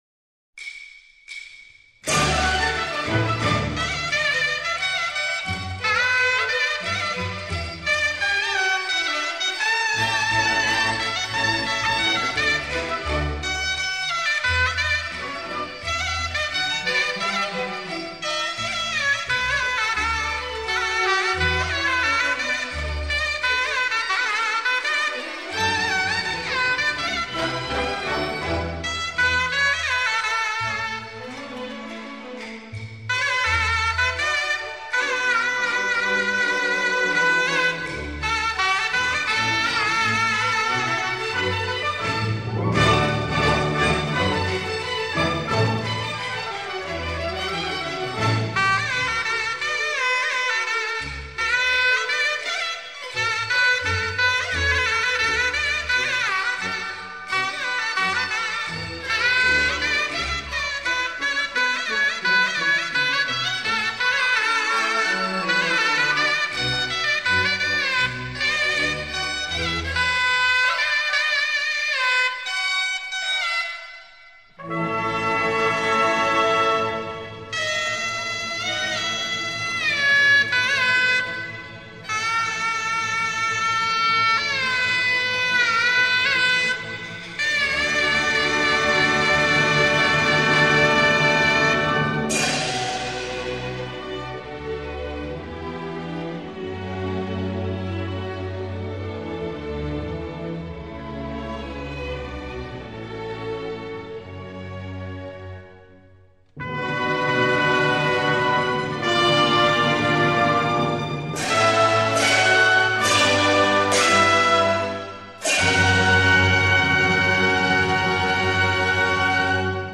现代京剧